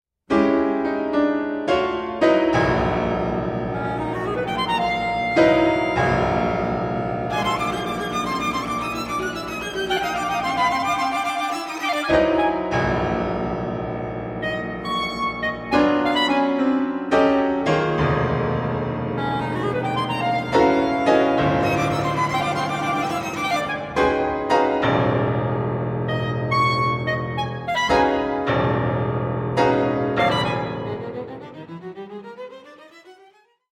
Clarinet
Cello
Piano
Violin